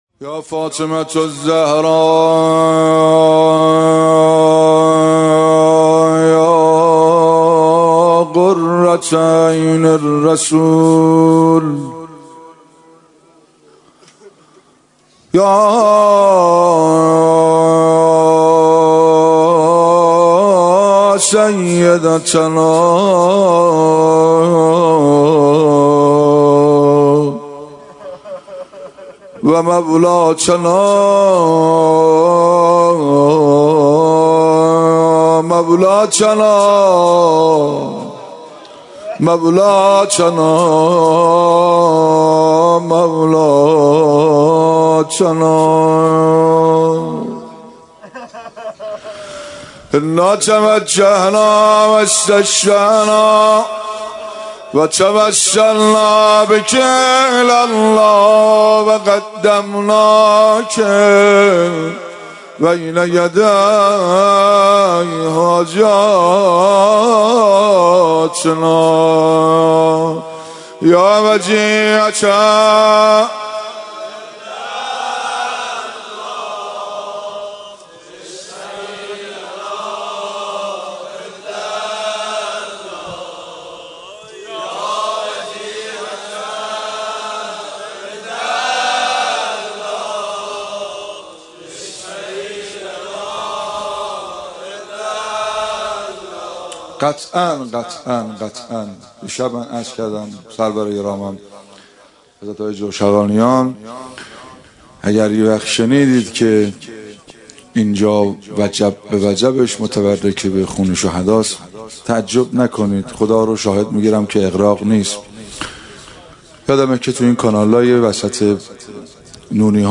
روضه
روضه و ذکر